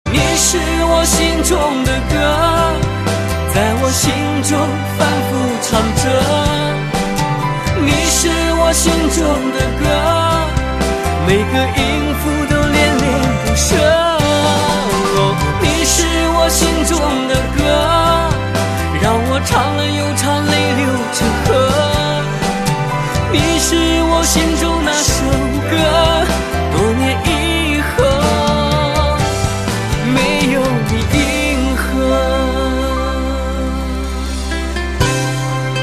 M4R铃声, MP3铃声, 华语歌曲 31 首发日期：2018-05-15 14:48 星期二